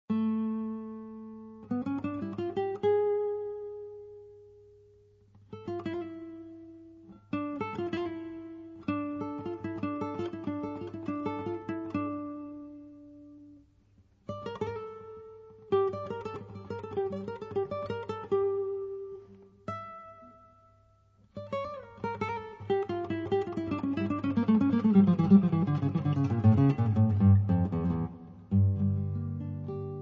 basso
batteria,percussioni
chitarra,voce
sax
• registrazione sonora di musica